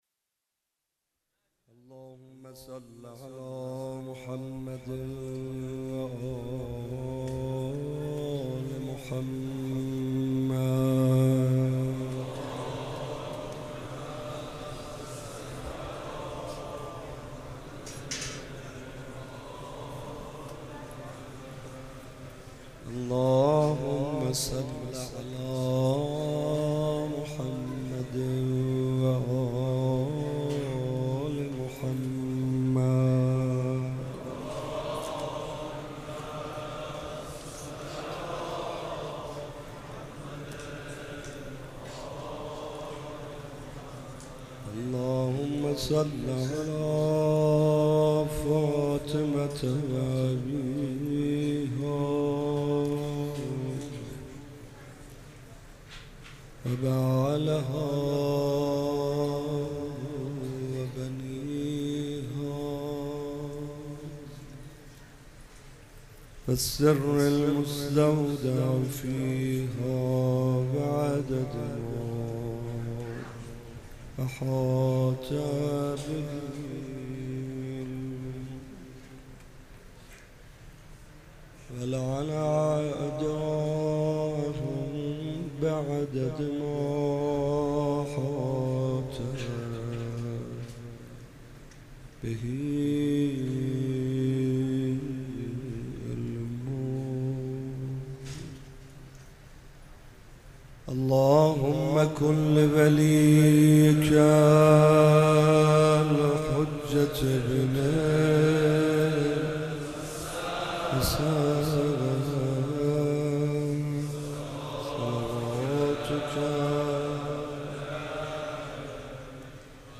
مداحی های شب بیست و ششم ماه مبارک رمضان در هیئت مکتب الزهرا(س)
مناجات با امام زمان(عج)